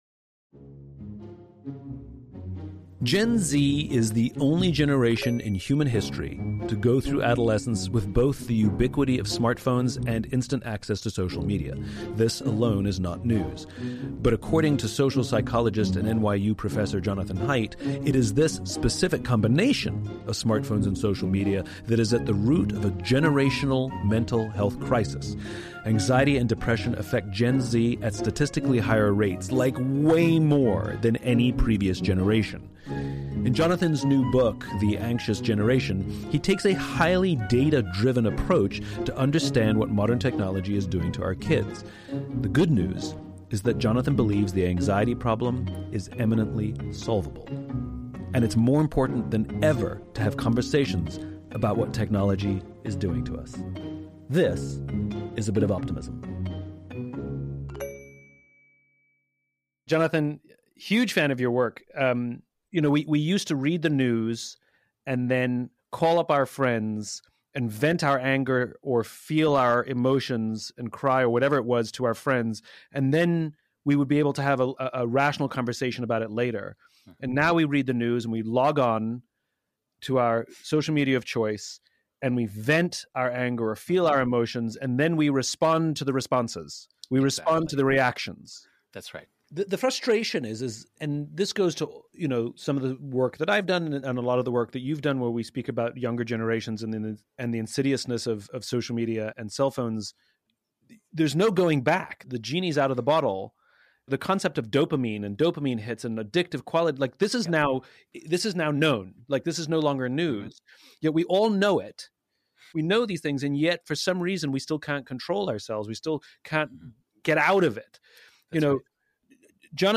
The Anxious Generation with social psychologist Jonathan Haidt
He shares some advice he has for concerned parents and tells me how we can fix the mental health crisis affecting our kids in just a few years time. This...is A Bit of Optimism.